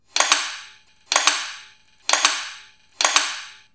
Trống Chiến